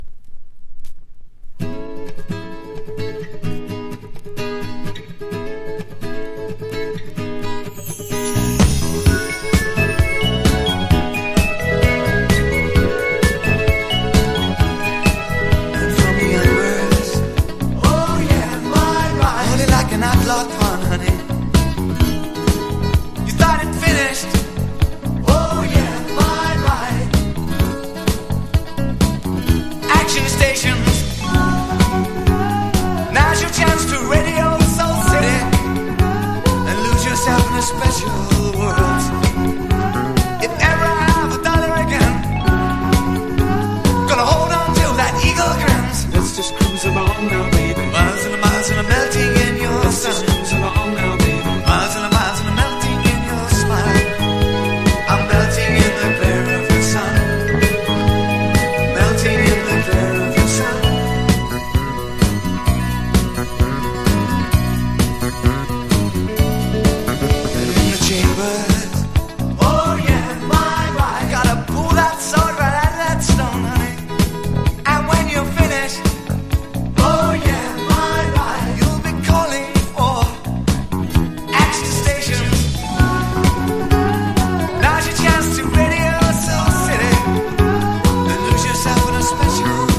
old形式 : 12inch / 型番 : / 原産国 : UK
NEO ACOUSTIC / GUITAR POP# 80’s ROCK / POPS